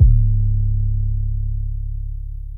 MB 808 (22).wav